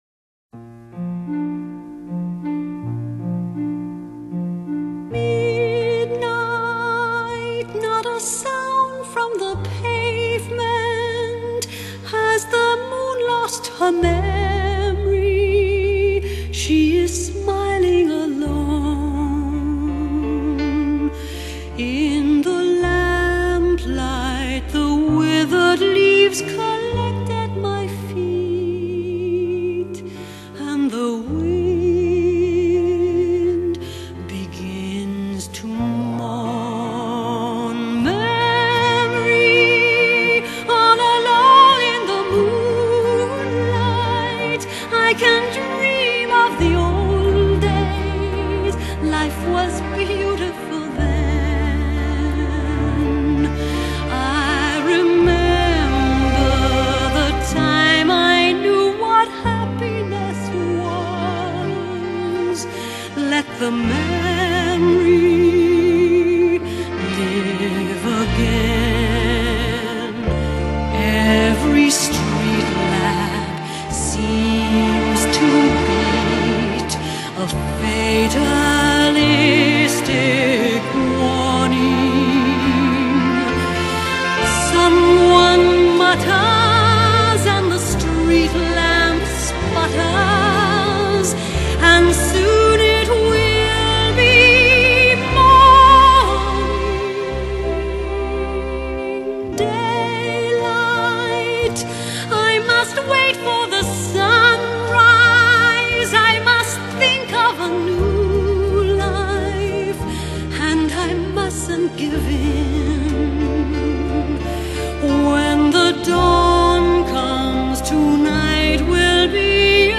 2012 Genre: Pop, Vocal Quality